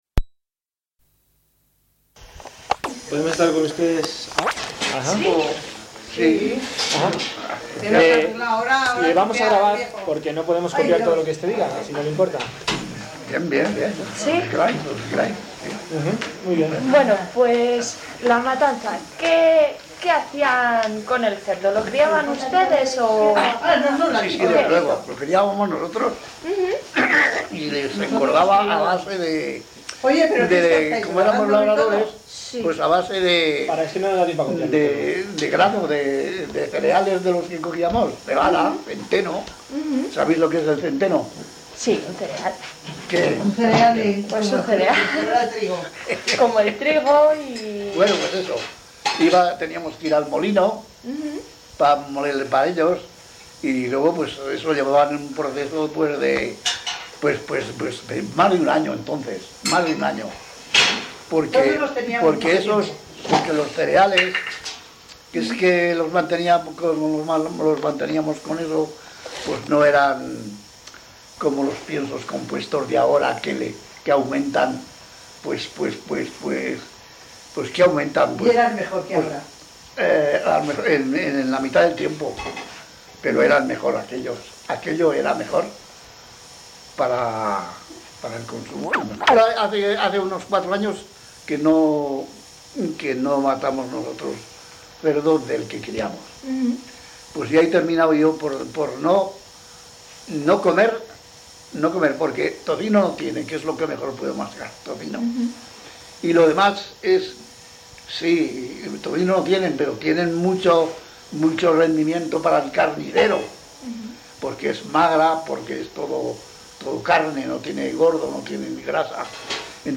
Locality Bronchales
Informants �I1:�var�n�-�87�a�os,�I2:�mujer�-�85�a�os�aprox.,�I3:�var�n�-�30�a�os�aprox. Topics 1.�Matanza�del�cerdo 6.�Agricultura 8.�Otras industrias 10.�Vida�religiosa 12.�Educaci�n 15.�Fiestas�populares 16.�Sanidad�y�salud 18.�Ej�rcito/Servicio�Militar